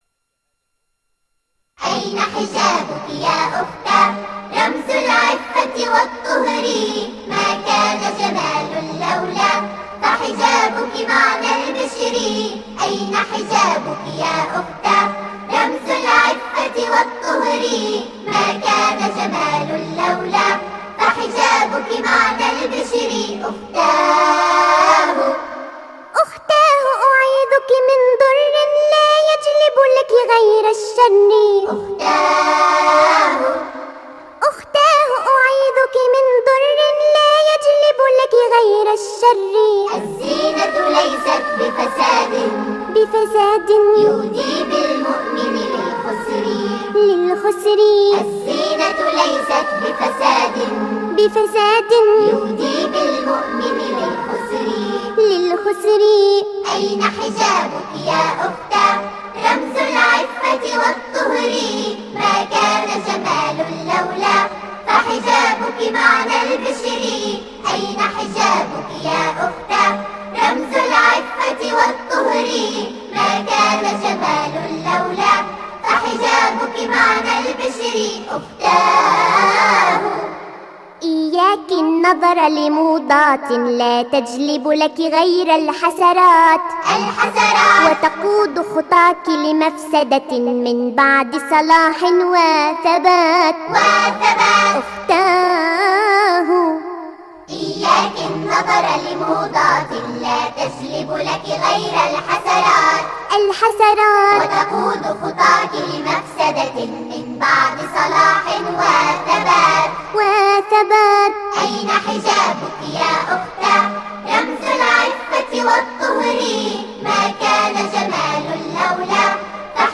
Nasheed for Children